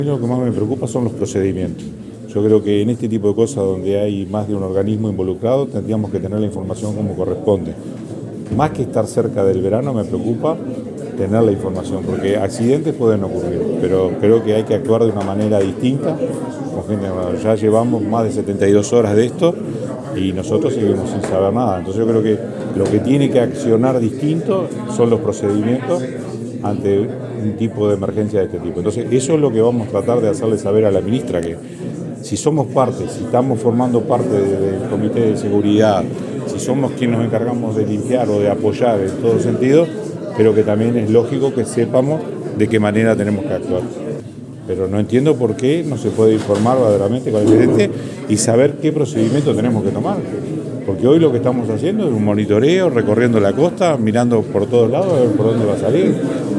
Informe
INTENDENTE-ABELLA-HIDROCARBUROS.mp3